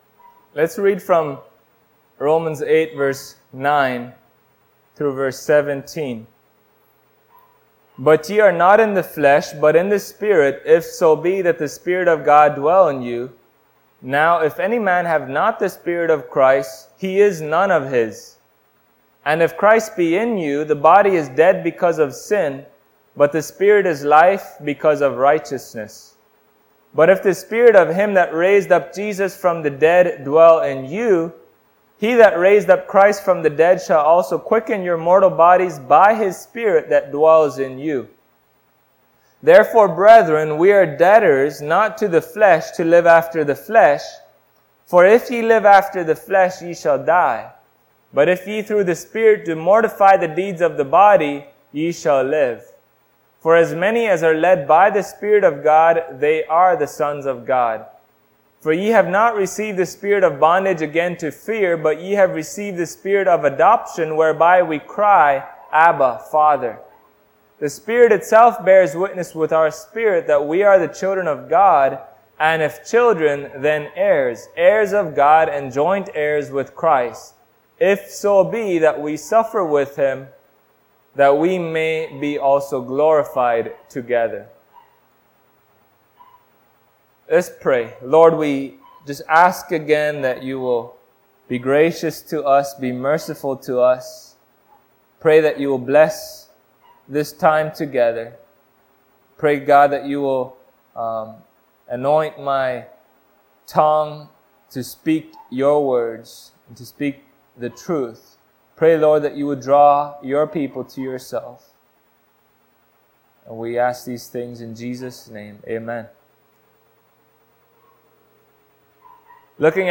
Service Type: Sunday Morning Topics: Evidence of Salvation , Spirit of Christ